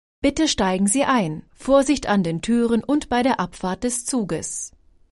• Taster 1 > Bitte steigen Sie ein_Vorsicht an den Türen und bei der Abfahrt des Zuges